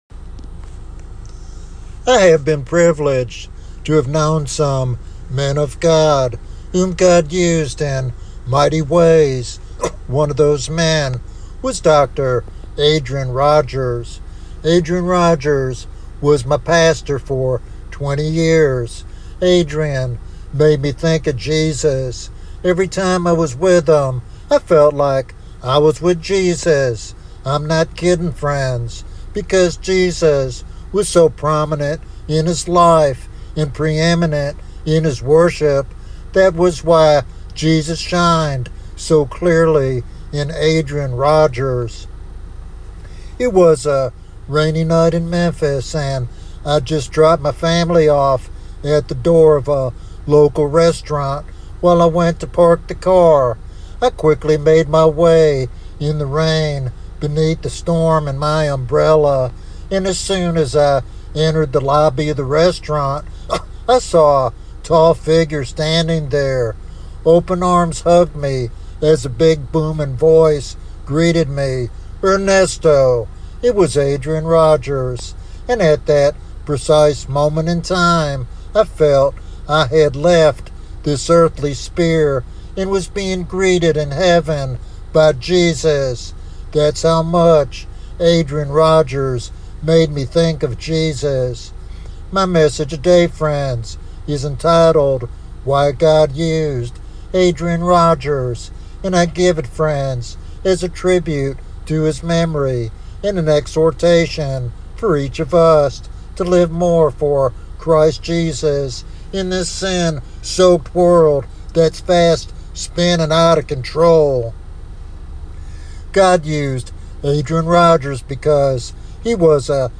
In this heartfelt biographical sermon